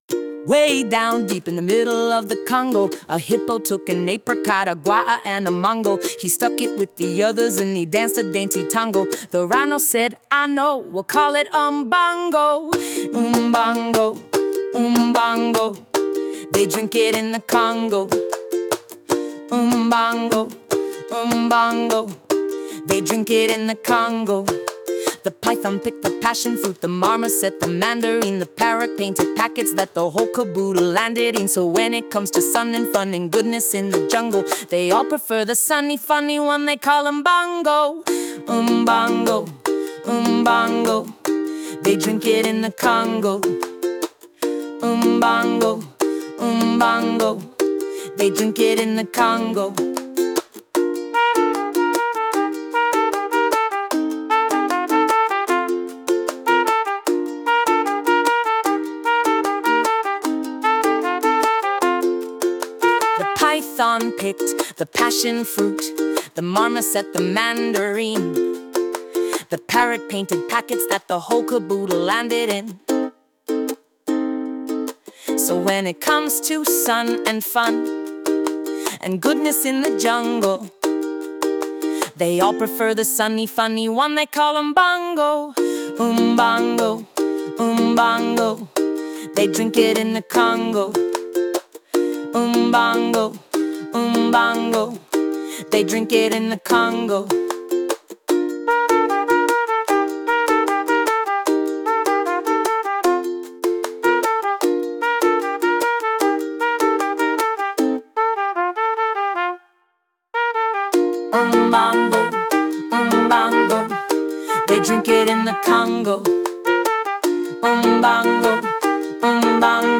Clear vocals, synthpop
No synths here!